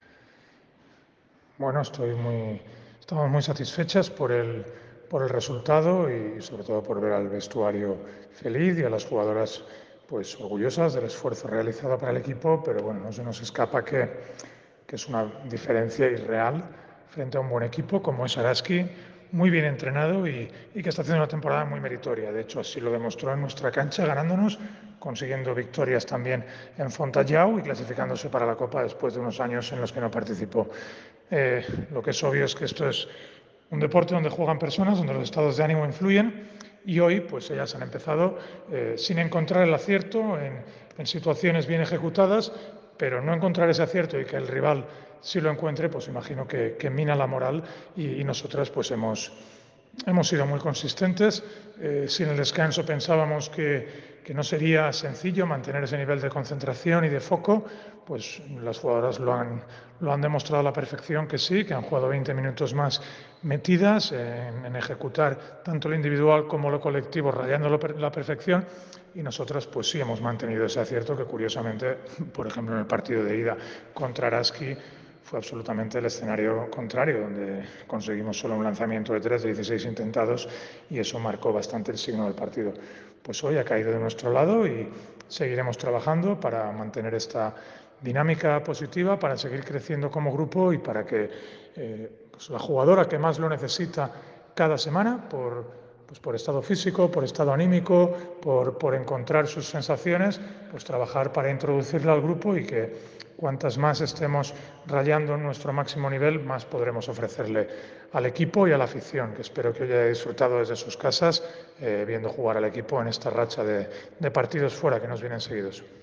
Declaraciones post partido